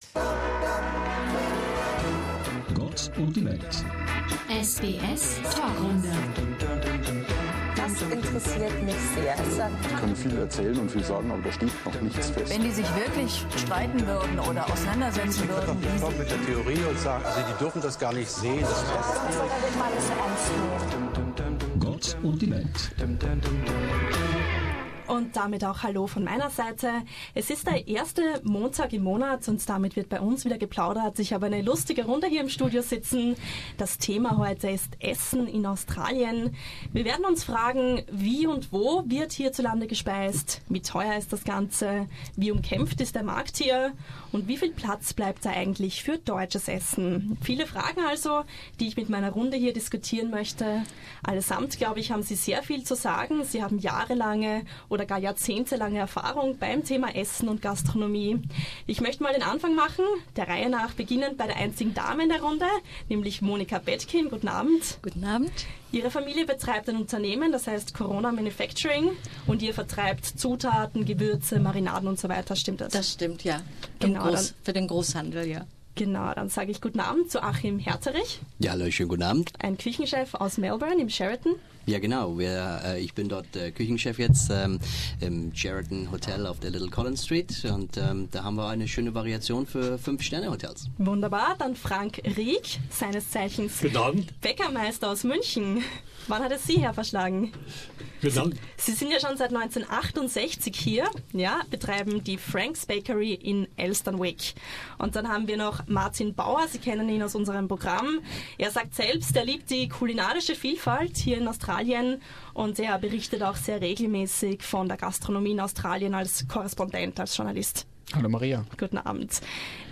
Welchen Platz hat deutsches Essen auf diesem umkämpften Markt? Welche Tendenzen lassen sich beobachten und wieso nehmen viele hohe Preise und Wartezeiten in Kauf? Unsere deutschspachigen Gäste haben diskutiert.